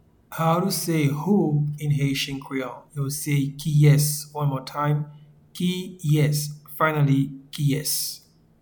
Pronunciation and Transcript:
Who-in-Haitian-Creole-Kiyes.mp3